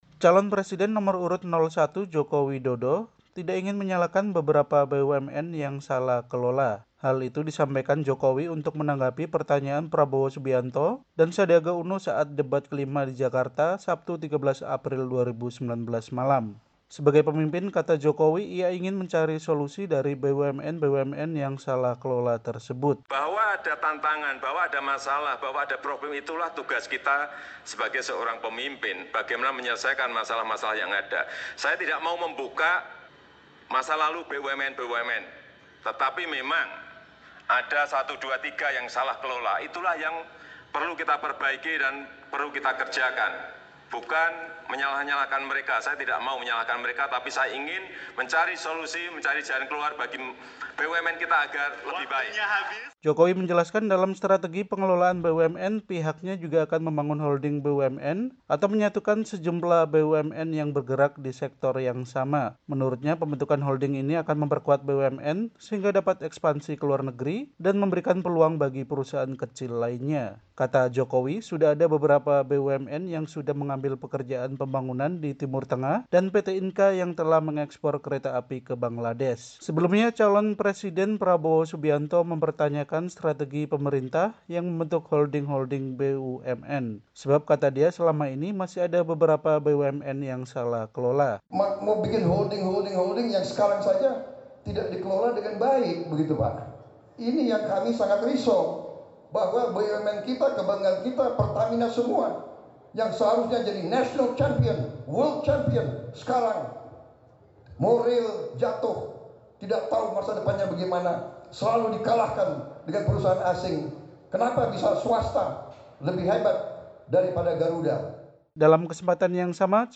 Calon presiden Joko Widodo mengakui ada beberapa badan usaha milik negara (BUMN) yang salah kelola, saat debat terakhir di Hotel Sultan, Jakarta, Sabtu, 14 April 2019.